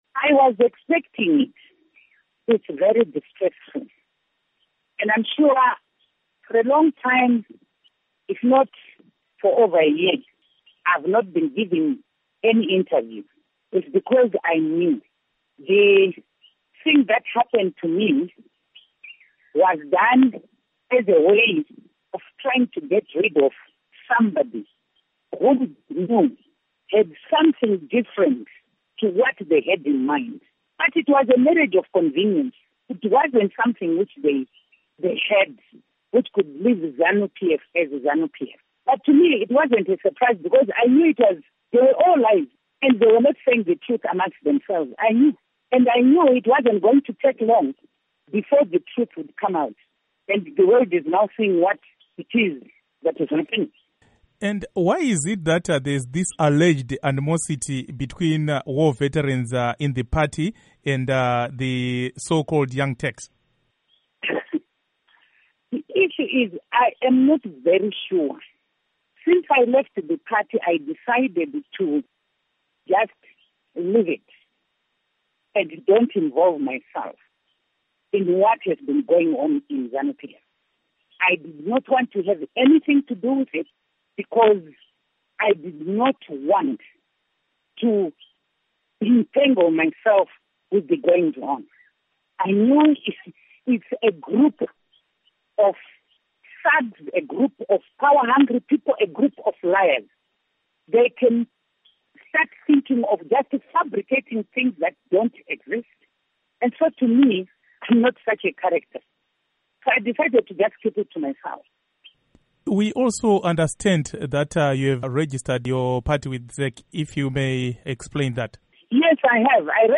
In an exclusive interview with Voice of America’s Studio 7, Mrs. Mujuru was asked if she is indeed now the interim president of the party, she replied, “Yes please.”
Interview With Joice Mujuru